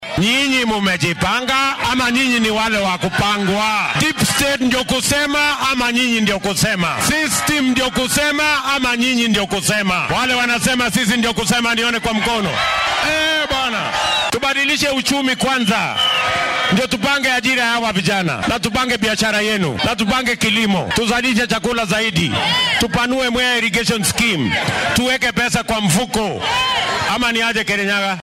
Madaxweyne ku xigeenka dalka William Ruto oo shalay isku soo bax siyaasadeed ku qabtay magaalada Kerugoya ee ismaamulka Kirinyaga ayaa mar kale sheegay in go’aanka kama dambeyska ah ee cidda la wareegaysa talada dalka ay leeyihiin codbixiyeyaasha. Waxaa uu xusay inay mudnaanta koowaad siin doonaan kobcinta dhaqaalaha iyo sare u qaadidda wax soo saarka.